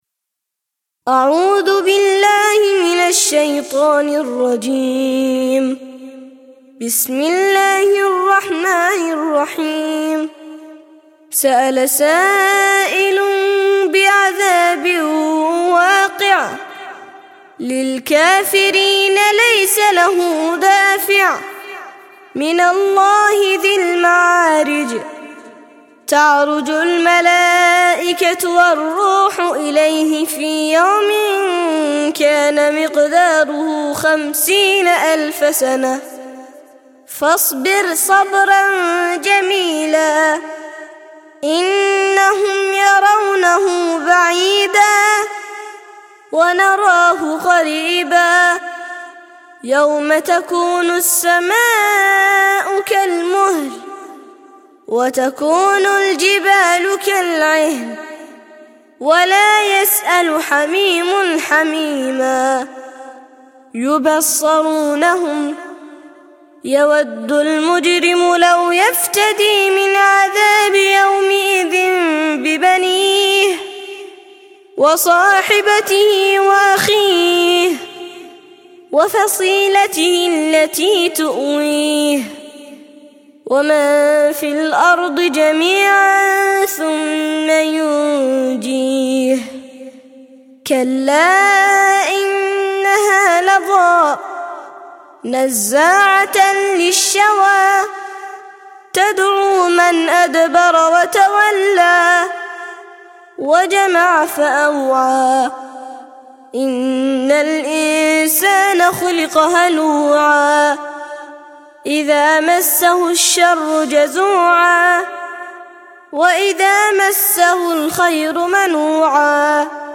70- سورة المعارج - ترتيل سورة المعارج للأطفال لحفظ الملف في مجلد خاص اضغط بالزر الأيمن هنا ثم اختر (حفظ الهدف باسم - Save Target As) واختر المكان المناسب